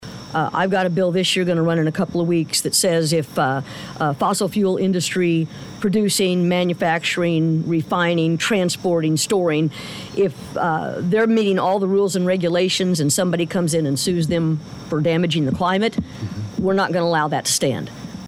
Fresh from the Bartlesville Chamber of Commerce "Eggs and Issues" event on Friday, where Representative John B. Kane, Senator Julie Daniels, and Representative Judd Strom met with constituents, the trio joined us in studio for KWON Radio's CAPITOL CALL program powered by Phillips 66